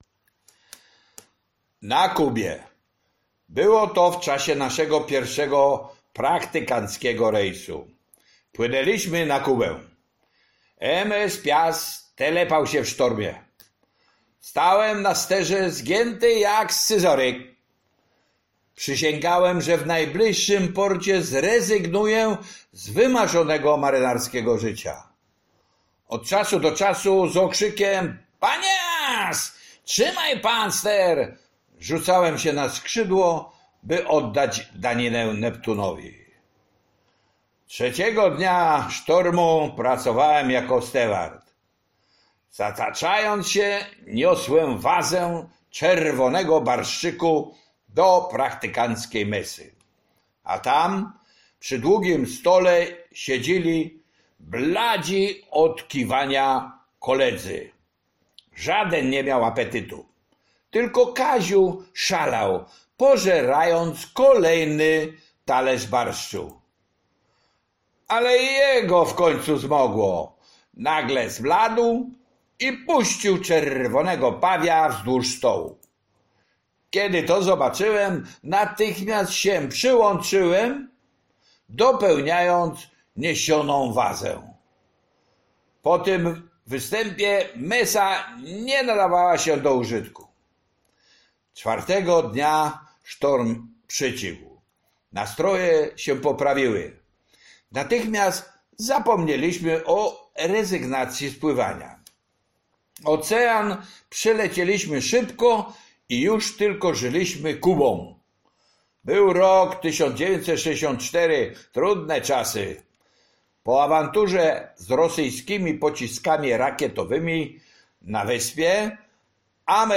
Życie marynarskie (audiobook). Rozdział 2 - Na Kubie - Książnica Pomorska